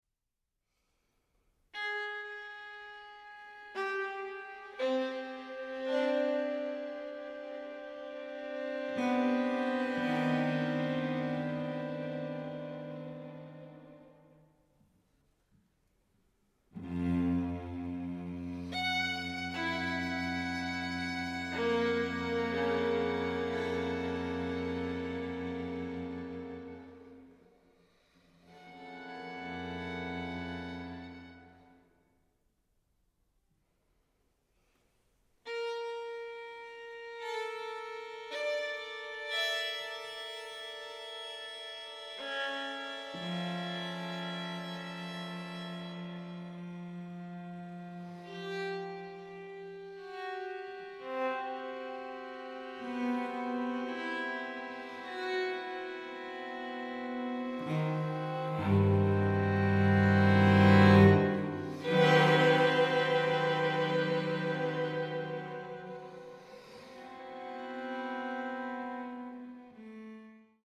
violin
viola